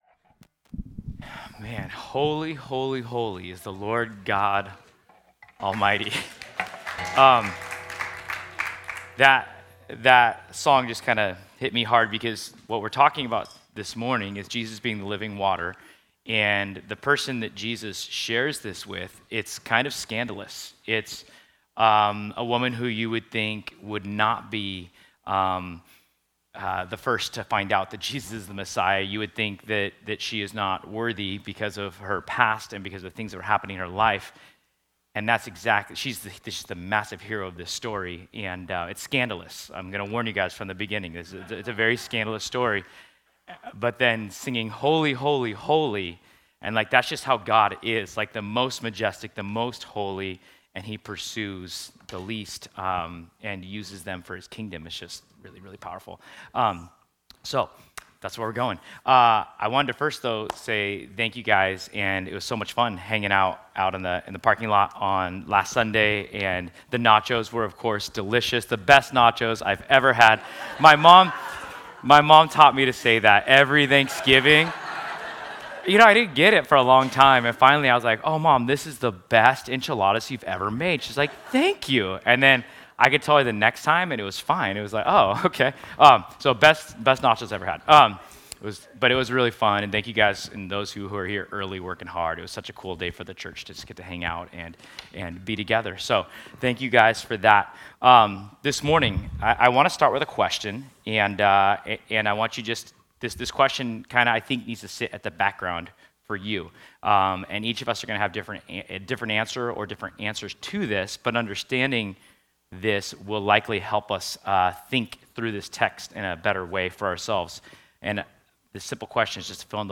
Service Type: Sunday